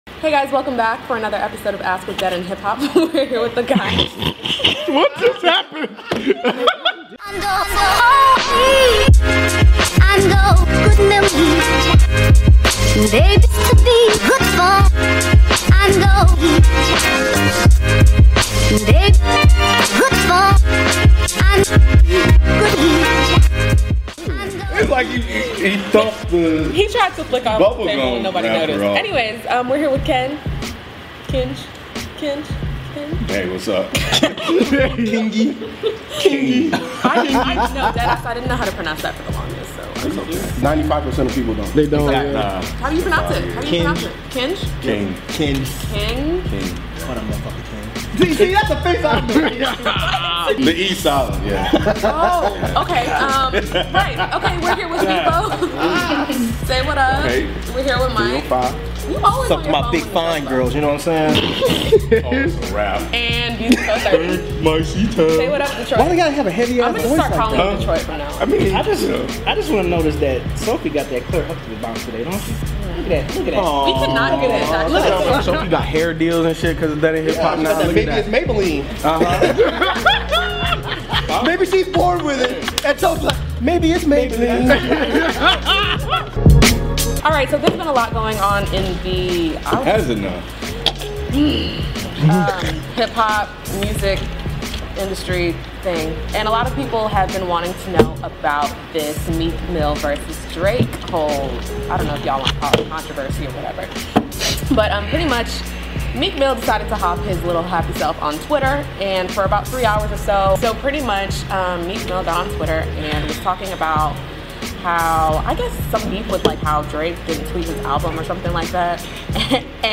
where questions submitted by fans are answered by the DEHH crew on the spot